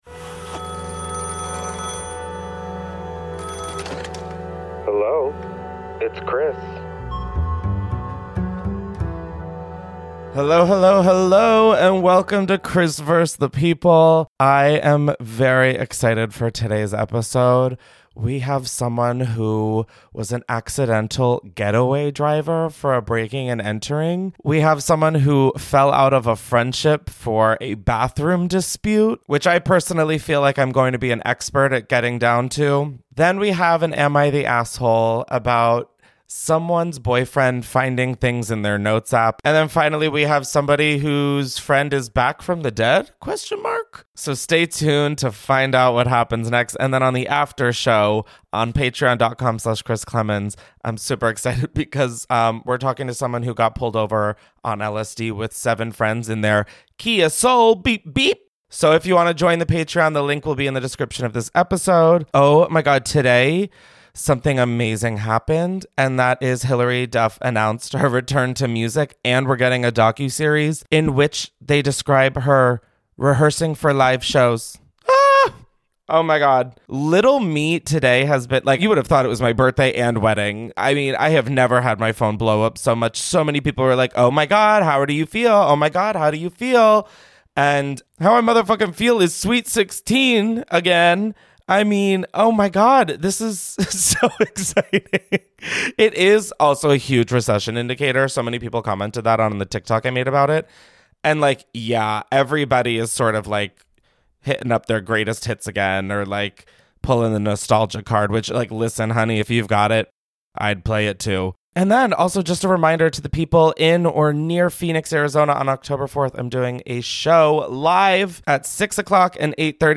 First, we hear from someone who accidentally became a getaway driver for a breaking and entering. Then, a caller shares how a long-time friendship fell apart over a bathroom dispute.
And finally, one caller swears their friend is literally back from the dead.